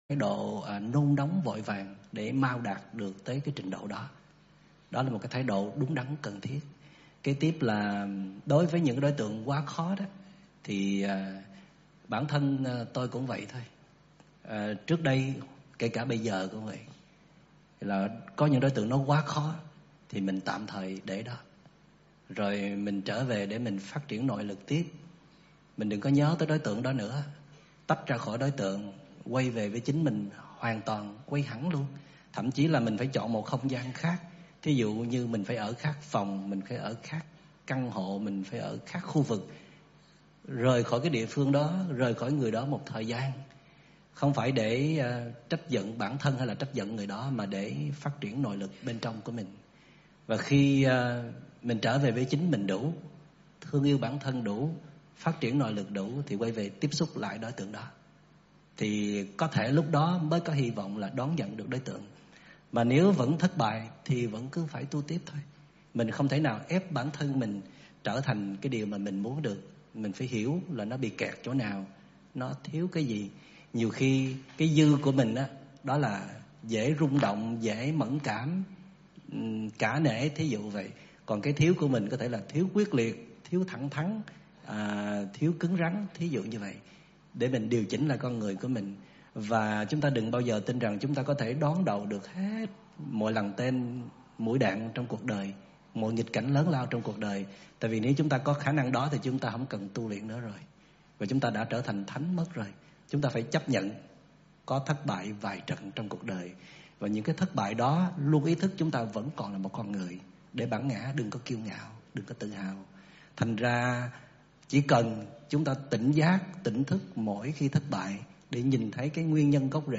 Mp3 thuyết pháp
Mp3 Thuyết Pháp